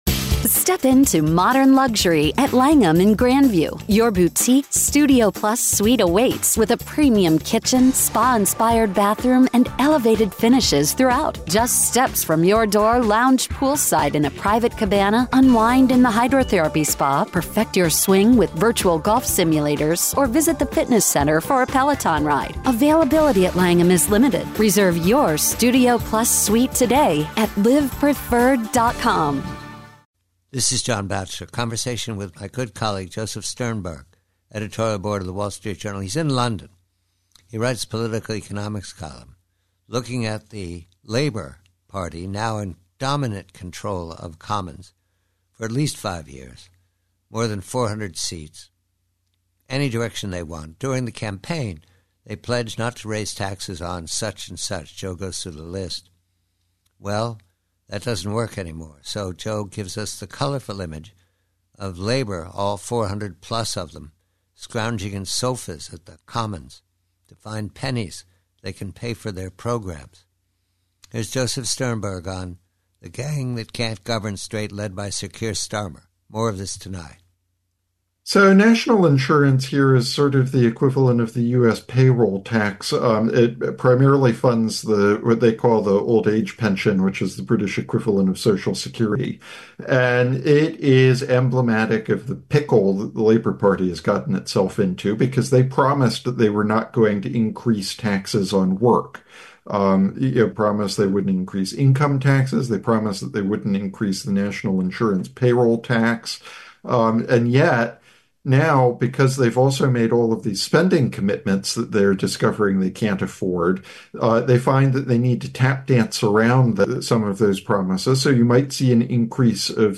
Preview: Conversation